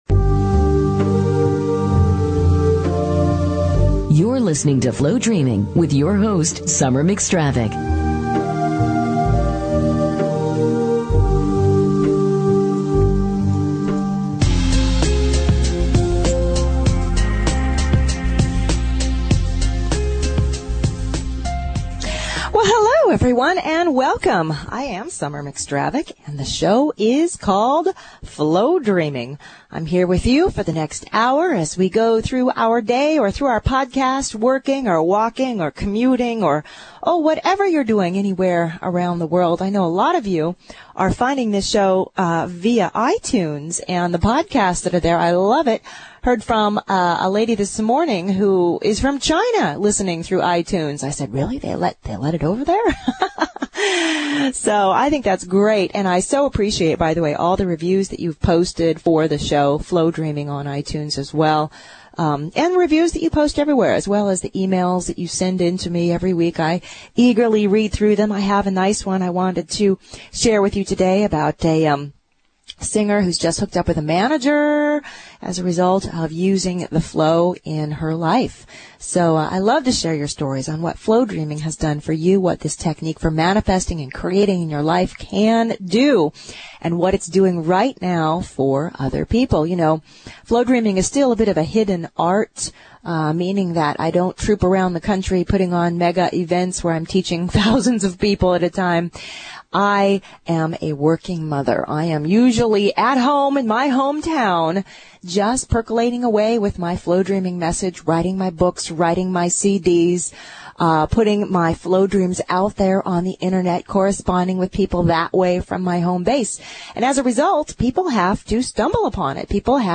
Talk Show Episode, Audio Podcast, Flowdreaming and Courtesy of BBS Radio on , show guests , about , categorized as
Flowdreaming is a fun, fast-paced show about manifesting and Flow energy.